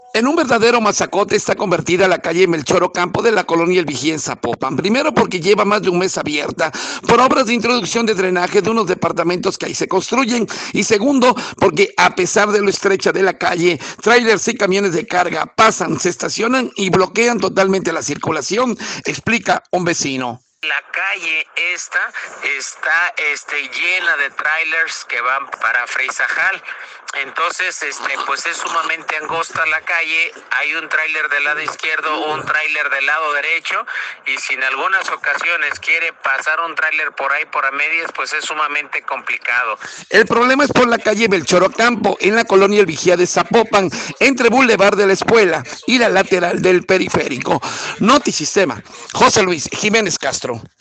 En un verdadero masacote está convertida la calle Melchor Ocampo de la colonia El Vigía en Zapopan. Primero porque lleva más de un mes abierta por obras de introducción de drenaje de unos departamentos que ahí se construyendo y segundo porque a pesar de lo estrecha de la calle, tráilers y camiones de carga pasan, se estacionan y bloquean totalmente la circulación, explica un vecino.